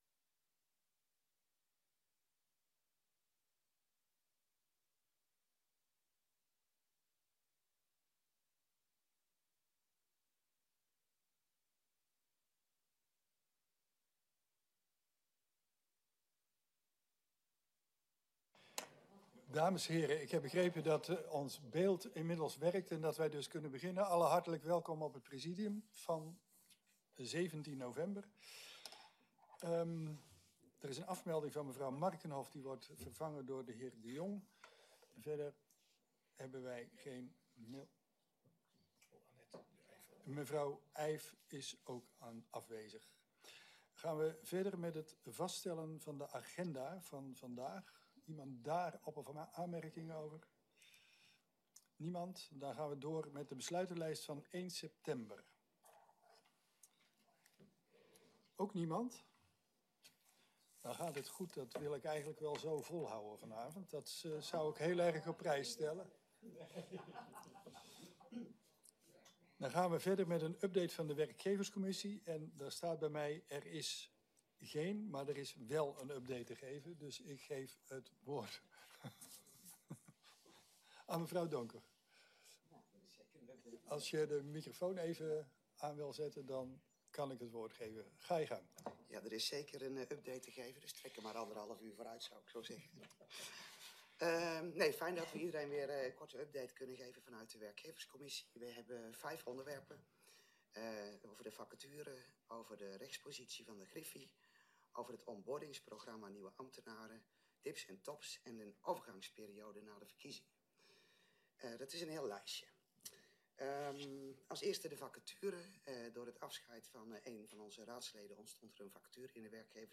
Locatie: Voorrondezaal Lingewaal